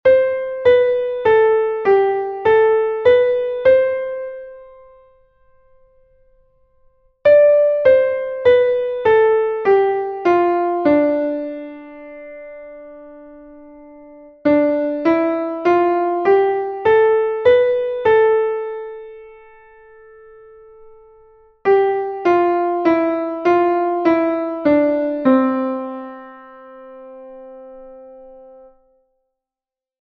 Here there are four 6/8 time signature exercises.